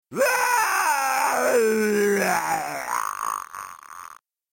hilarious scream, and his spirit floats away... No time for a funeral, though.